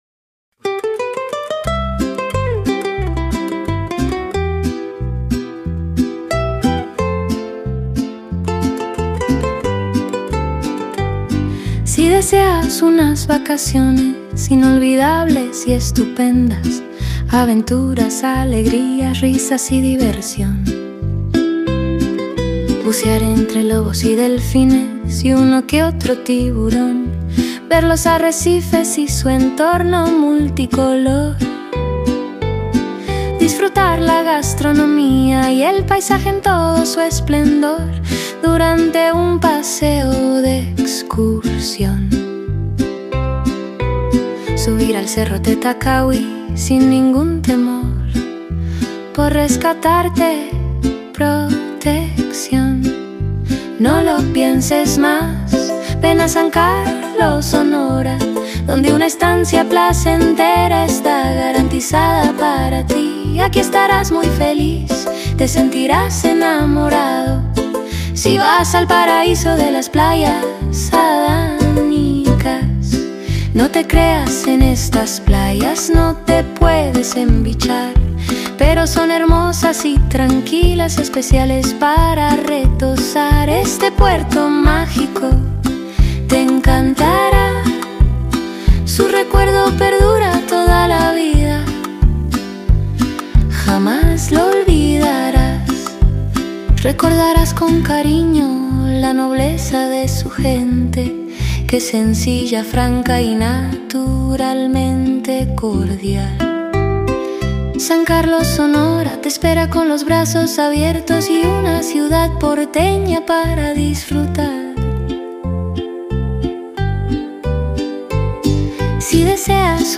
Genre Country